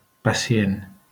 IPA[pəsiˈen]